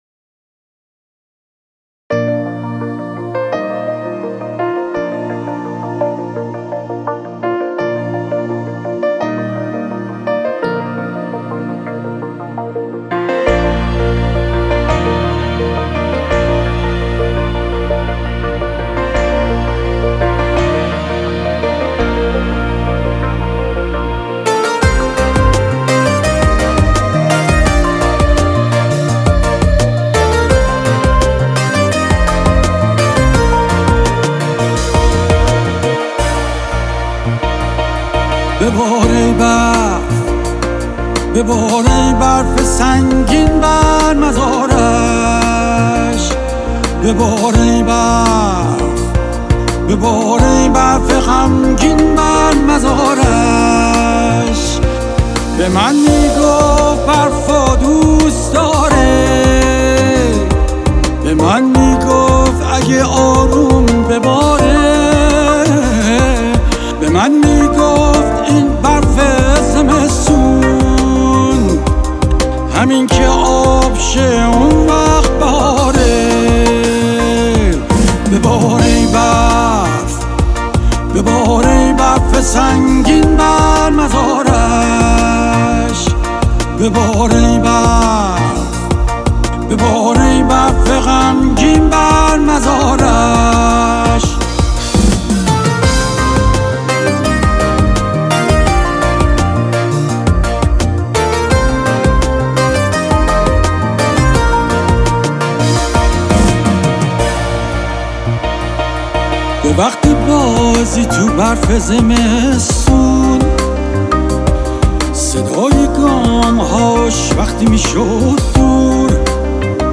* **فضا:** غم‌انگیز، نوستالژیک و عمیقاً عاشقانه